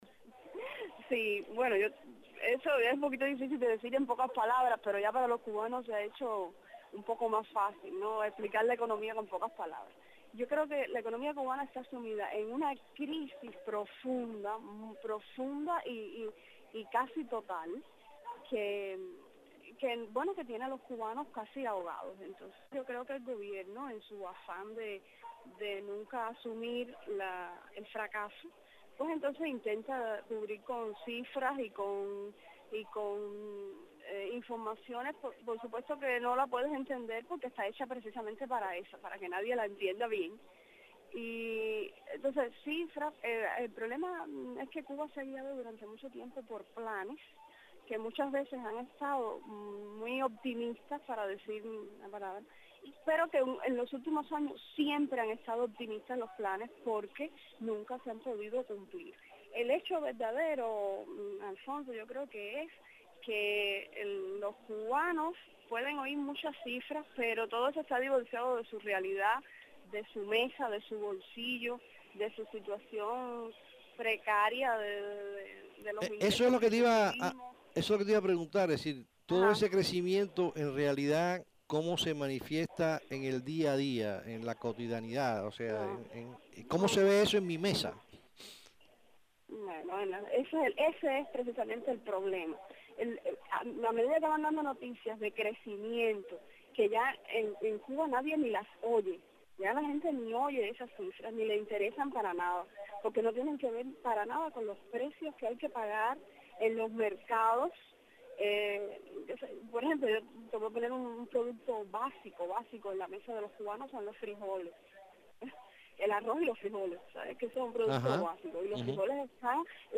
economista cubana